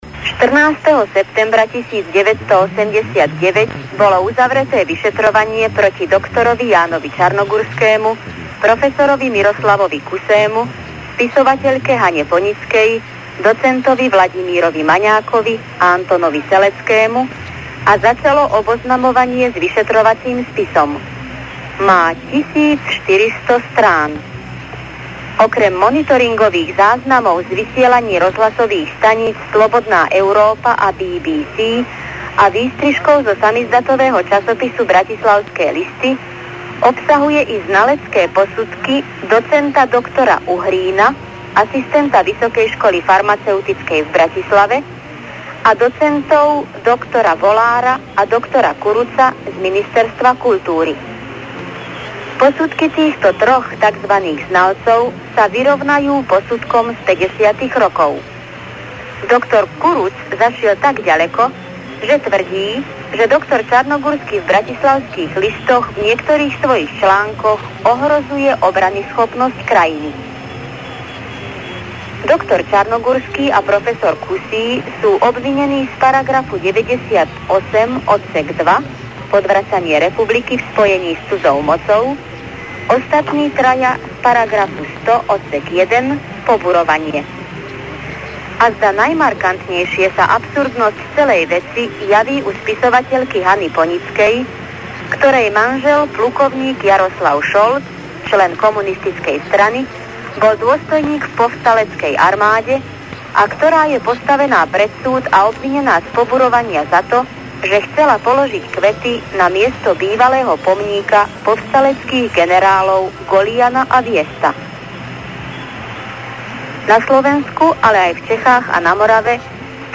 Z vysielania Hlasu Ameriky a Rádia Slobodná Európa  (august – november 1989)    August 1989